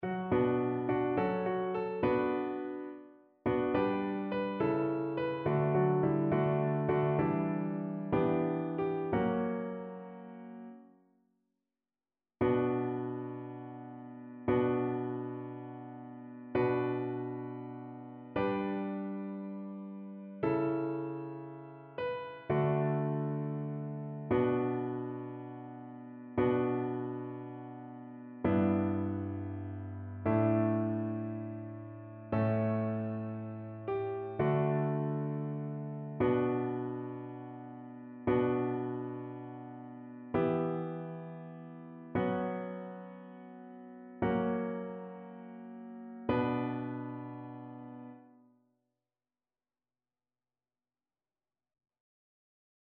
annee-c-temps-de-l-avent-3e-dimanche-cantique-d-isaie-satb.mp3